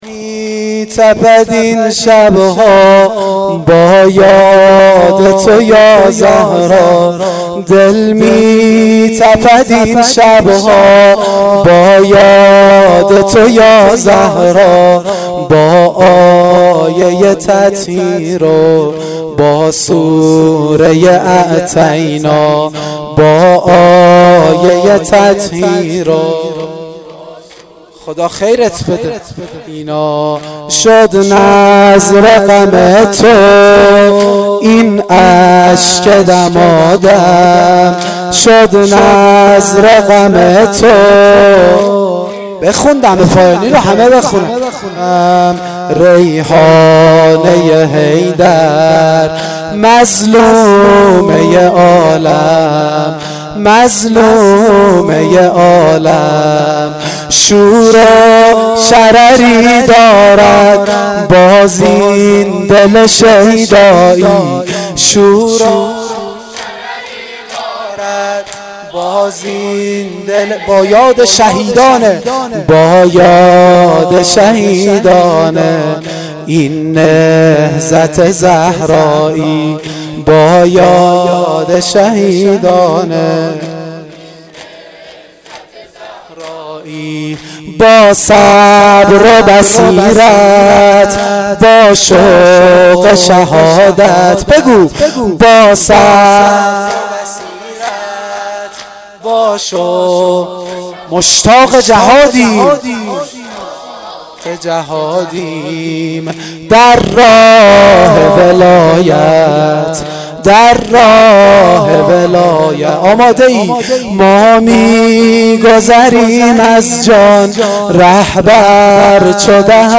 دم پایانی شب اول فاطمیه دوم